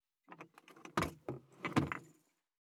224,机に物を置く,テーブル等に物を置く,食器,グラス,コップ,工具,小物,雑貨,コトン,トン,ゴト,ポン,ガシャン,
コップ効果音物を置く